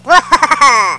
Chipmonk laughter 21K
hilaugh.wav